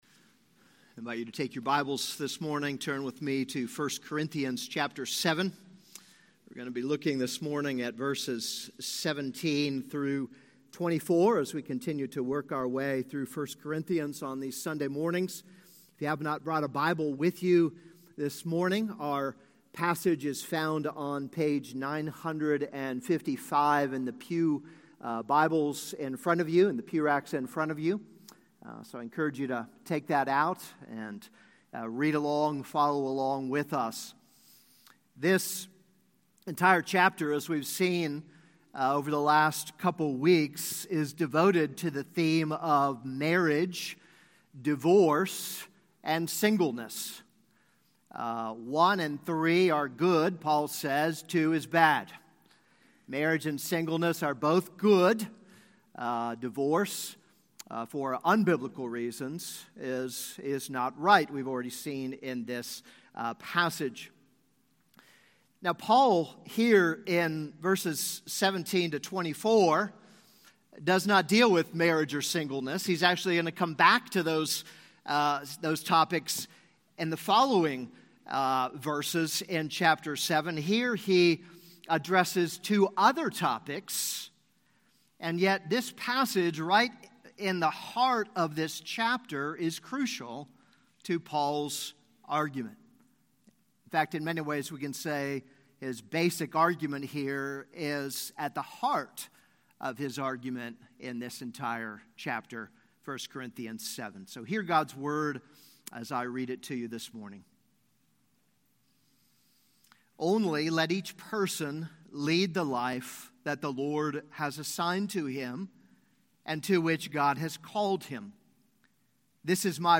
This is a sermon on 1 Corinthians 7:17-24.